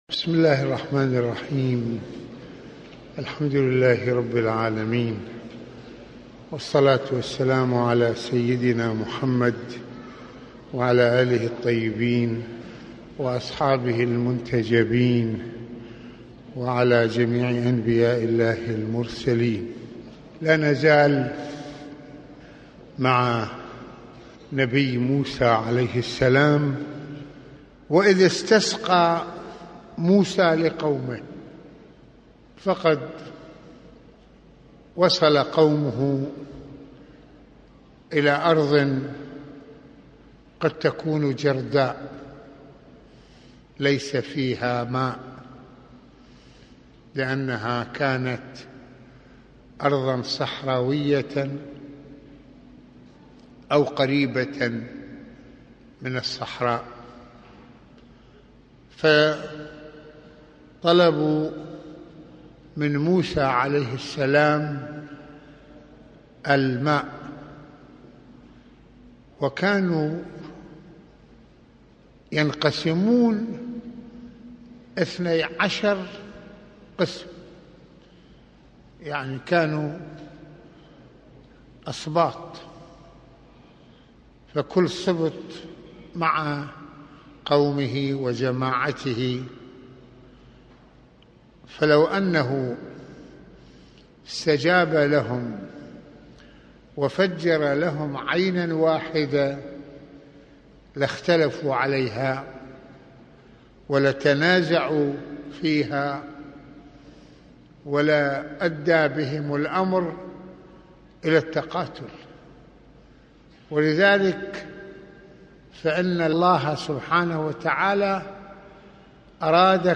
- يواصل سماحة المرجع السيد محمد حسين فضل الله (رض) في هذه المحاضرة حديثه عن موسى وقومه وطلبه الماء لهم، وإظهار معجزة تفجير الماء من الحجر اثني عشر عيناً بعدد أسباط بني إسارئيل وتوجيهات موسى لقومه بالإصلاح وعدم الإفساد، وإمعان قومه في المشاغبة المستمرة ويتطرق سماحته إلى فقدان بني إسرائيل لروح العزة والكرامة والحرية والإرادة بمخالفتهم وقتلهم لأنبيائهم ...